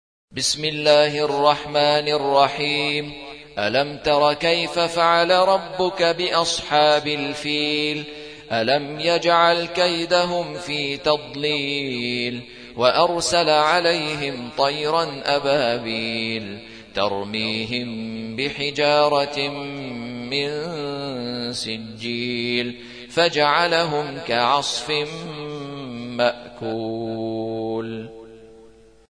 105. سورة الفيل / القارئ